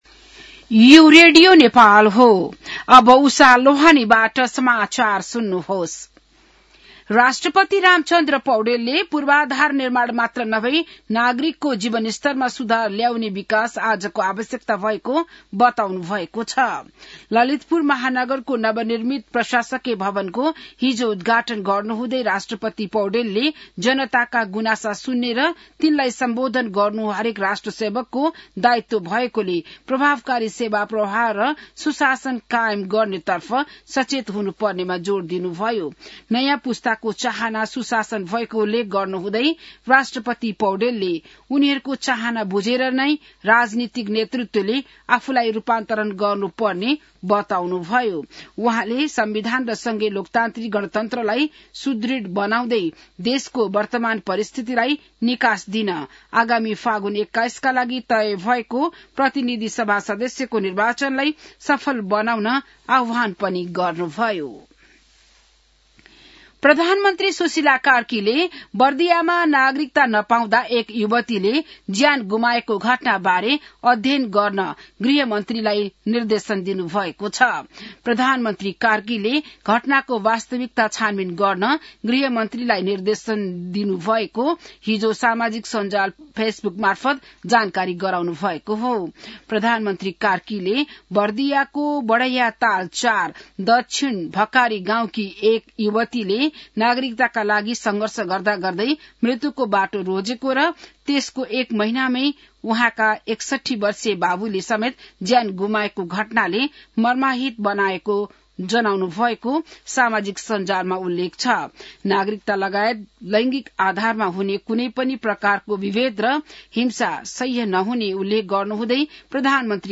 बिहान १० बजेको नेपाली समाचार : २८ असार , २०८२